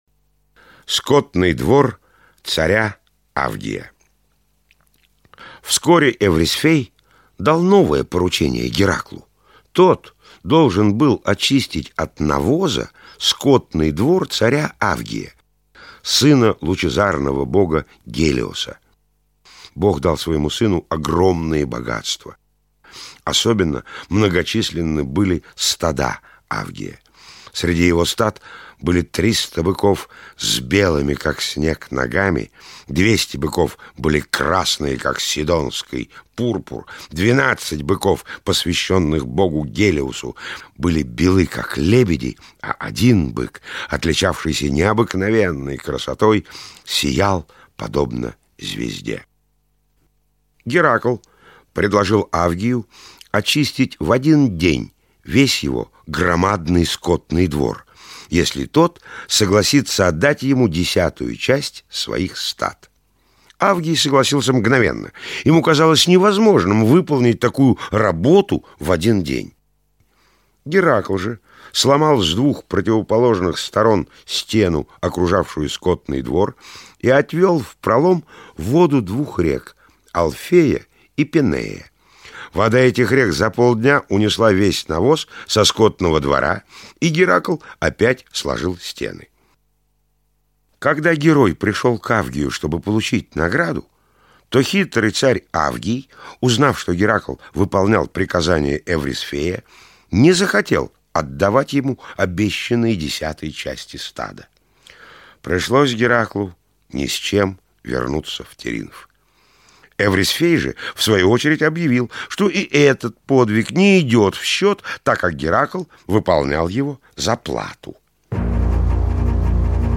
Аудиосказка «Подвиги Геракла. Скотный двор царя Авгия» – миф Древней Греции
Текст читает Николай Караченцов.